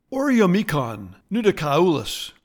Pronounciation:
O-re-o-ME-con nu-di-ca-U-lis